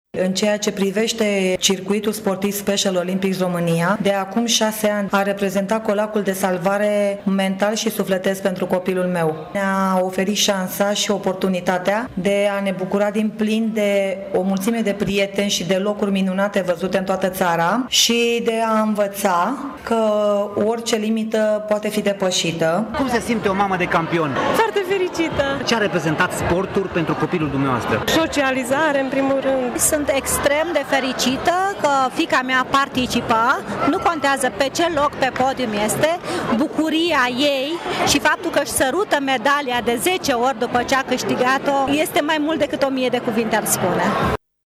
La festivitățile de premiere, bucuria tuturor este totală, iar părinții susțin că evenimente precum Special Olympics sunt esențiale pentru tonusul sufletesc al copiilor lor.